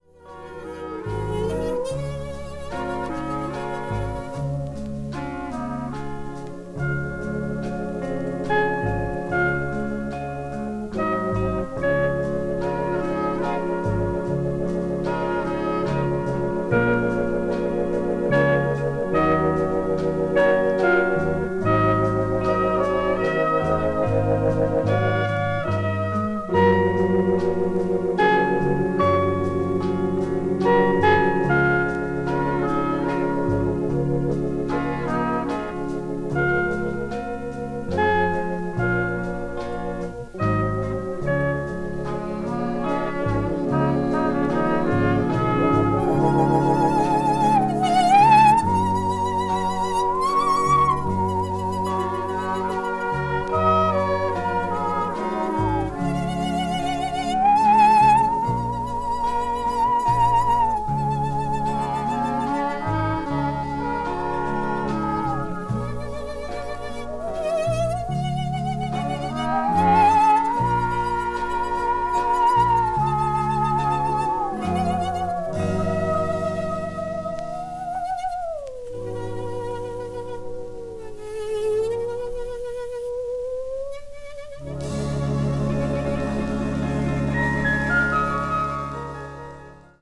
media : EX/EX(わずかにチリノイズが入る箇所あり)
east asia   instrumental   minyo   taiwan   world music